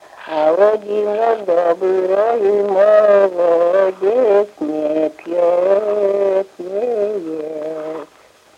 Отличные от нормативных окончания в именительном падеже единственного числа мужского рода прилагательных – безударная флексия –ый в соответствии с литературной -ой
/бо-га”-то-йот хв”аа-ста-йет зо-ло-то”й ка-зно”й бе-сщоо”-тно-йу:/